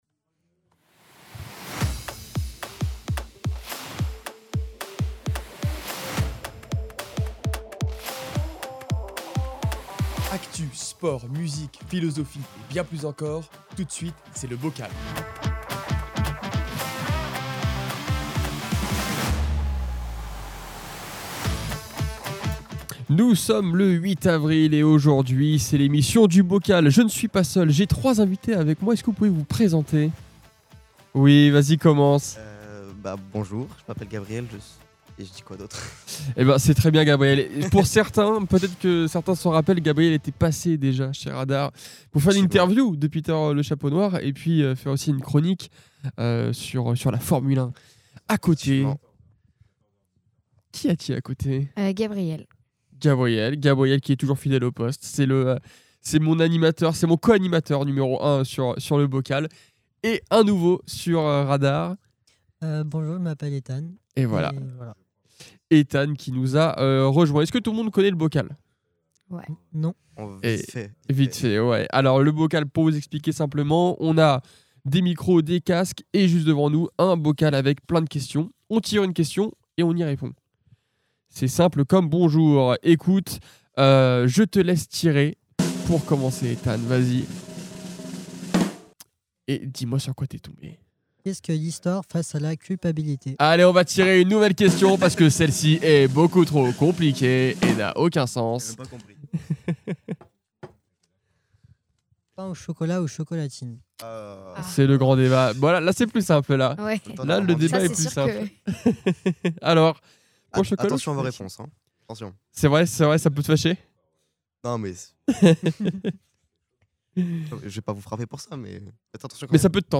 Actu, sport, vie associative et bien plus encore : ici, vous retrouverez toutes les interviews produites par RADAR 🎙